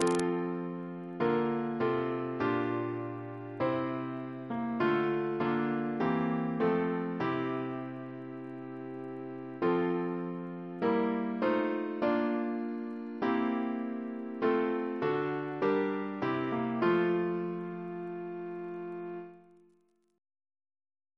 Double chant in F Composer: Edward John Hopkins (1818-1901), Organist of the Temple Church Reference psalters: ACP: 209; H1982: S434; OCB: 125